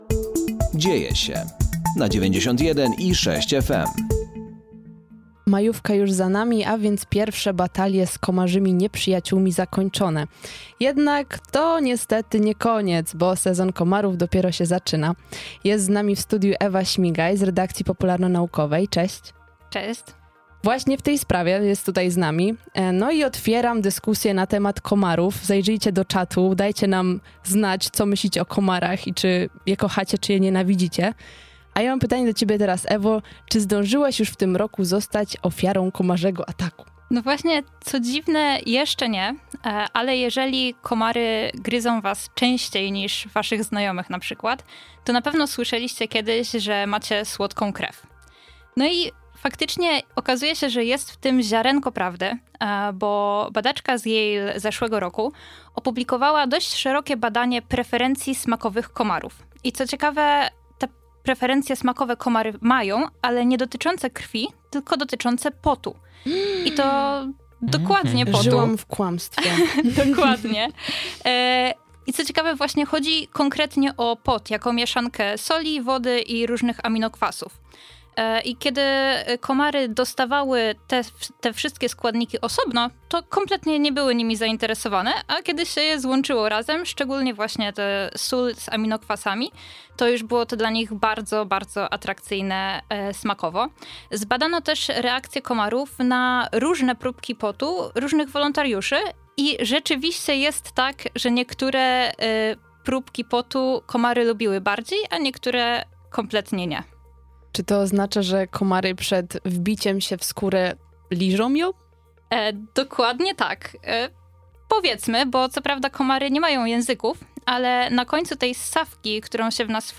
Zaatakowały już podczas majówki, a najgorsze dopiero przed nami. Dyskusja nt. komarów!